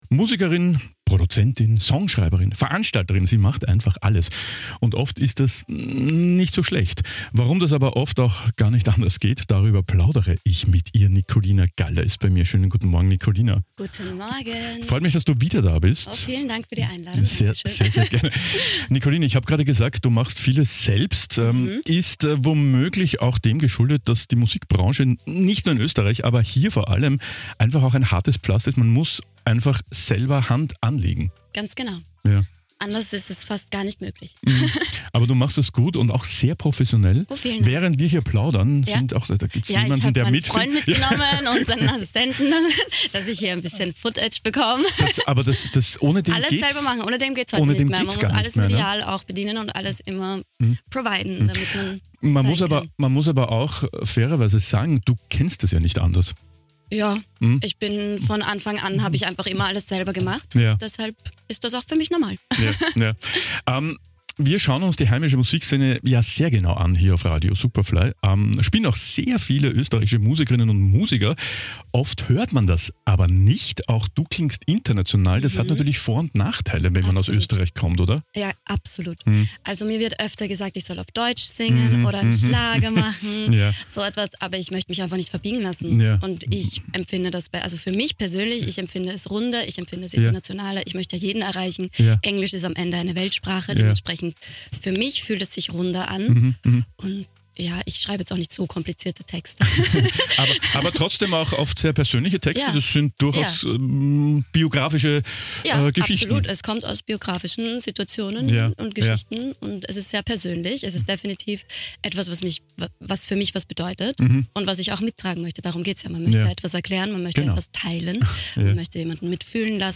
Superfly Featured | Im Gespräch